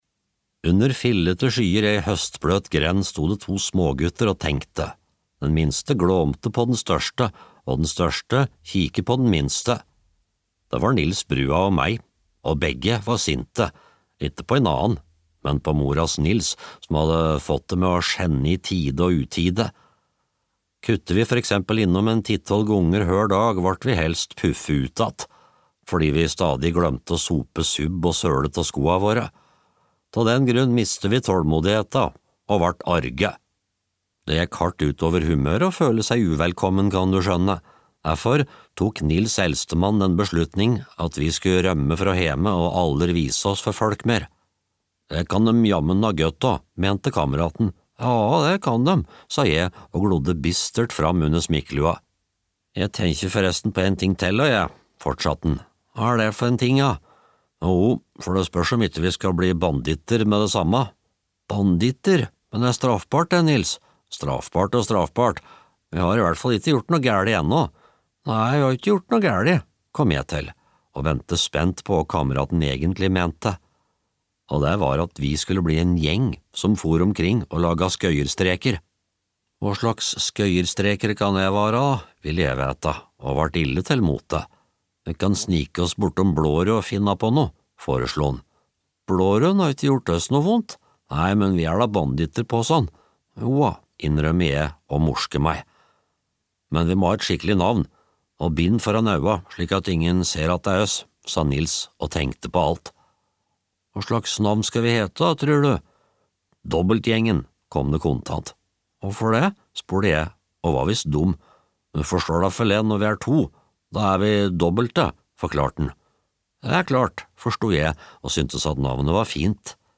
Dobbeltgjengen (lydbok) av Vidar Sandbeck